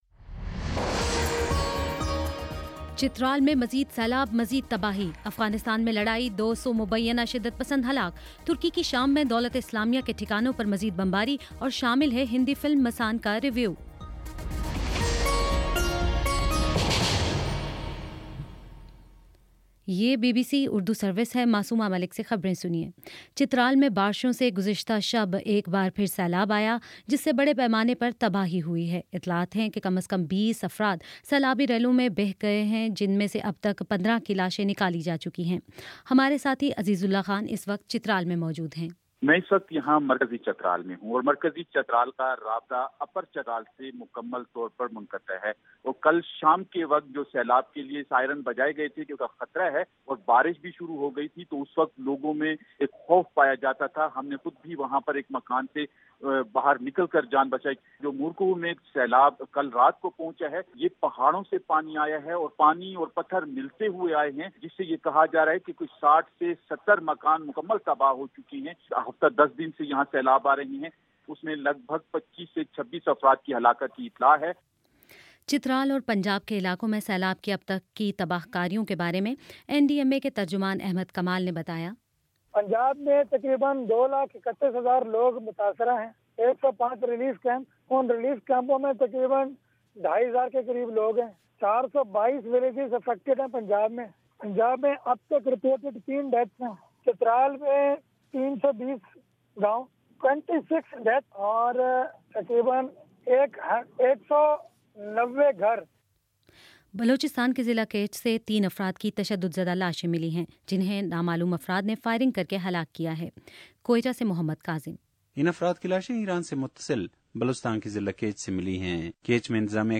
جولائی 25: شام چھ بجے کا نیوز بُلیٹن